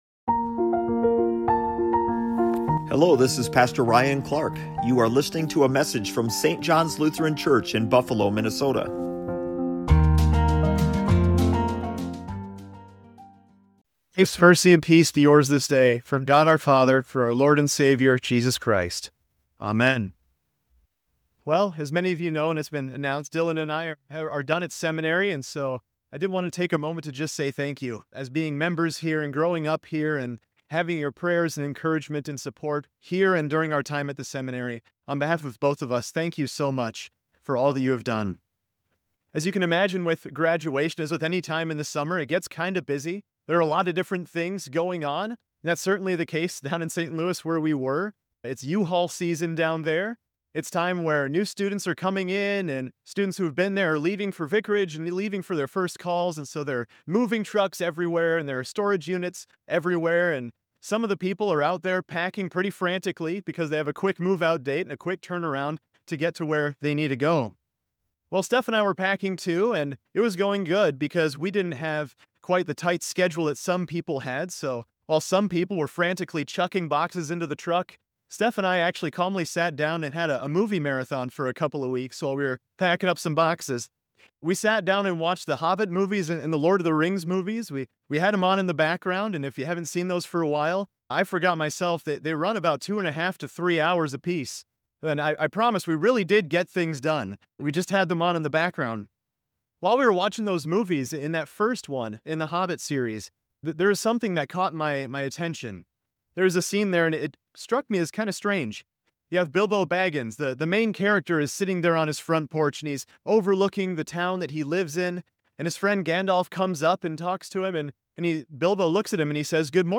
Part 3 in the Made for CommUNITY sermon series addresses forgiving one another from Colossians 3.